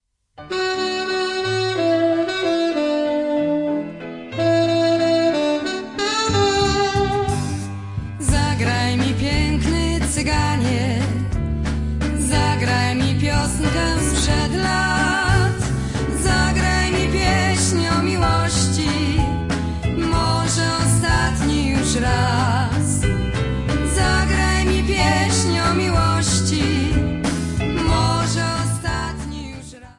Polish folk songs